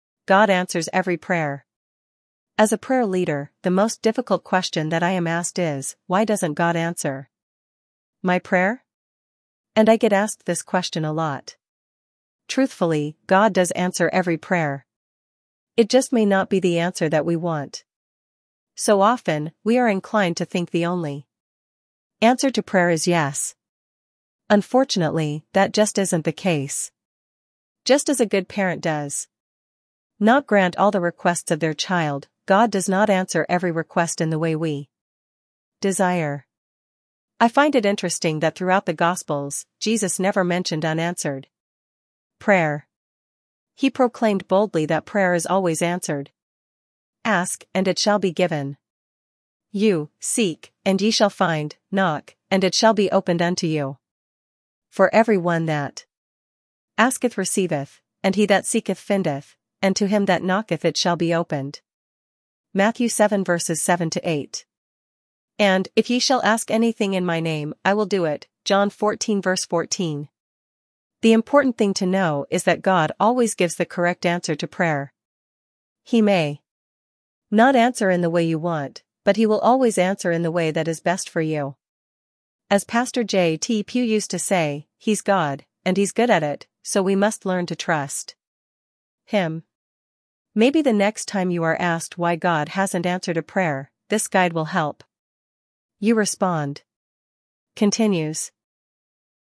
Access and listen to the AI Audio Information or see the Text/Word Information for your convenience or attention.